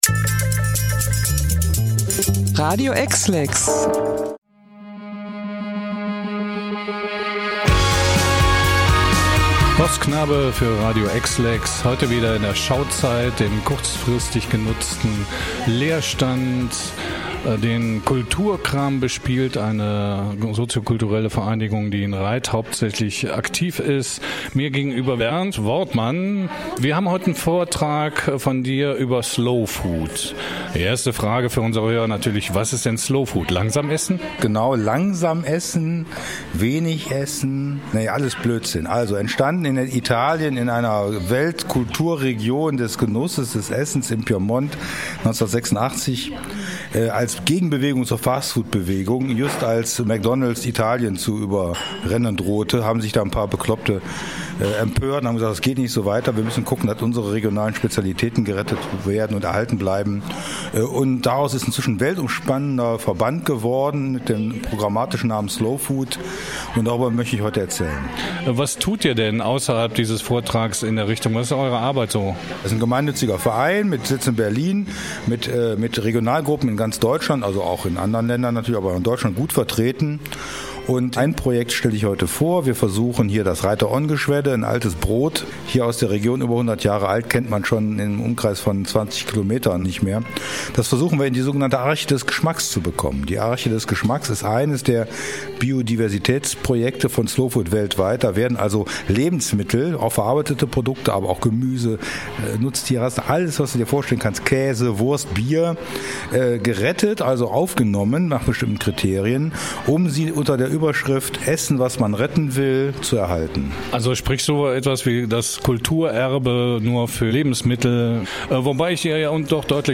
Radio-EXLEX-Interview-Slowfood.mp3